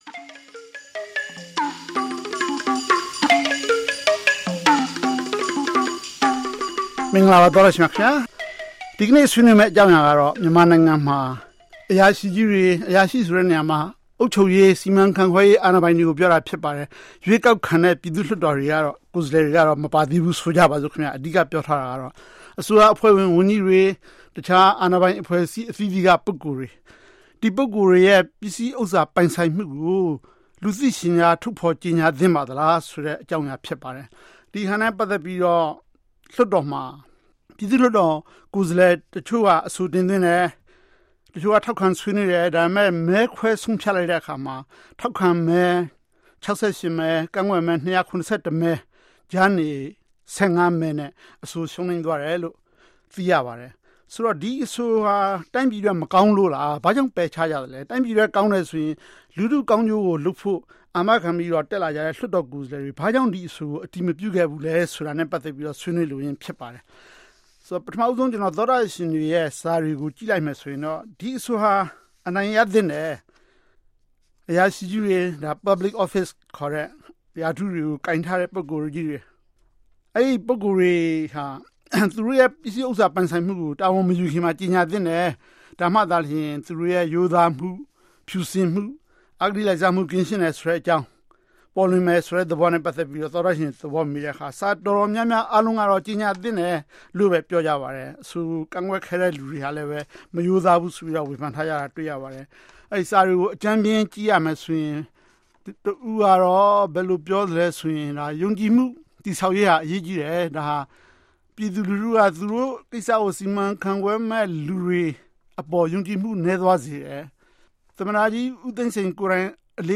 call in show